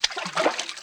STEPS Water, Walk 09.wav